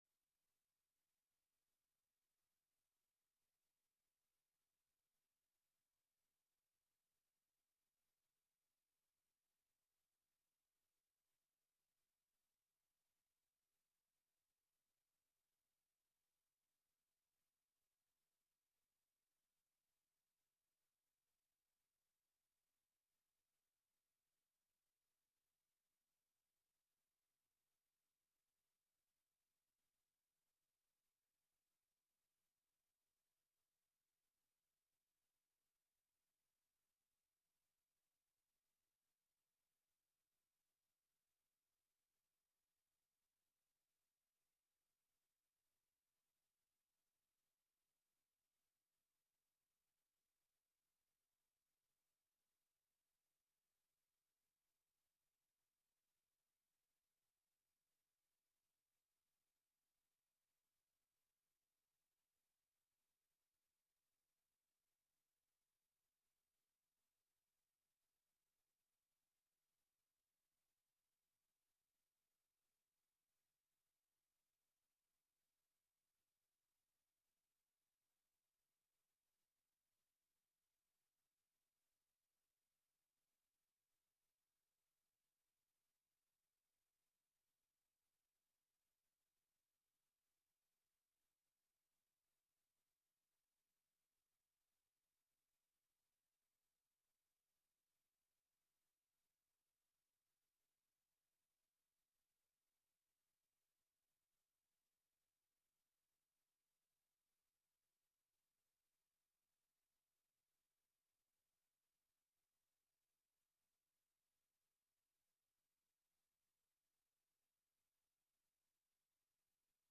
2026年3月22日溫城華人宣道會粵語堂主日崇拜